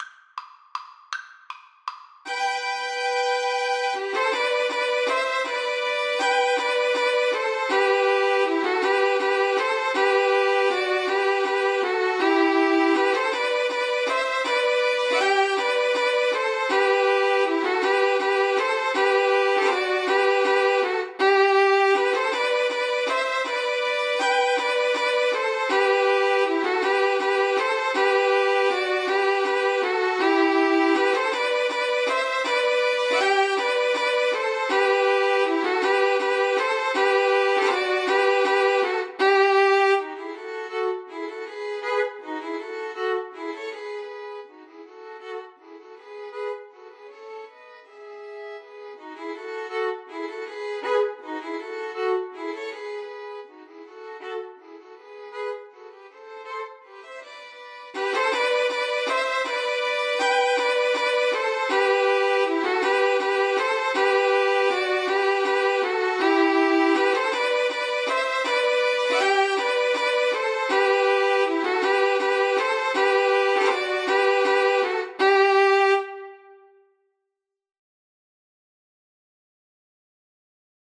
Violin 1Violin 2 Violin 3
3/4 (View more 3/4 Music)
Presto (View more music marked Presto)
Violin Trio  (View more Easy Violin Trio Music)
Classical (View more Classical Violin Trio Music)